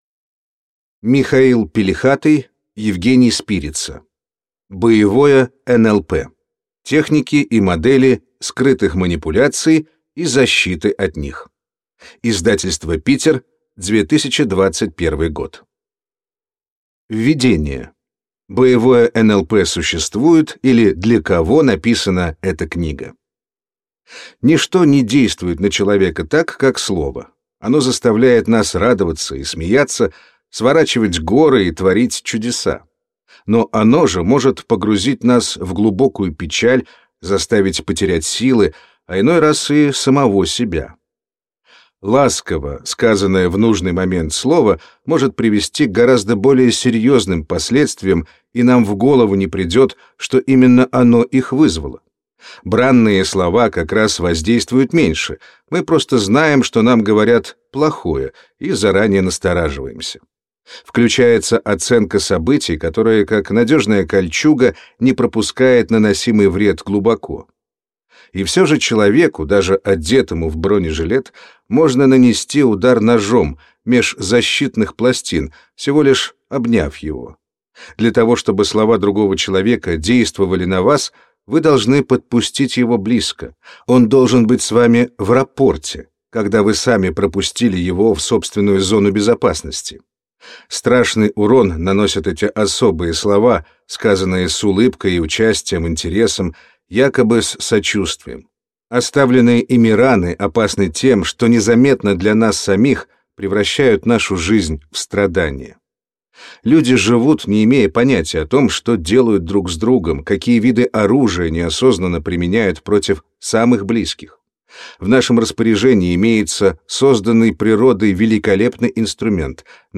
Аудиокнига Боевое НЛП: техники и модели скрытых манипуляций и защиты от них | Библиотека аудиокниг